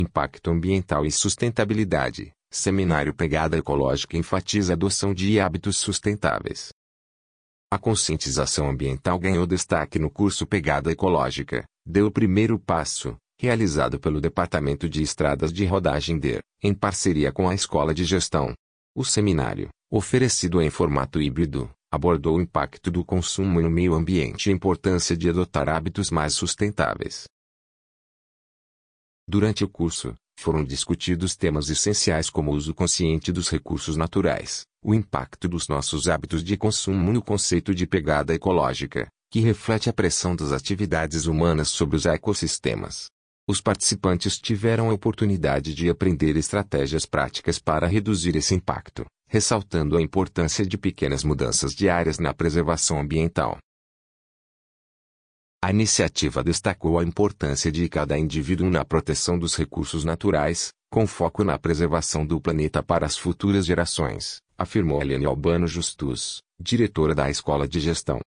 audionoticia_pegada_ecologica.mp3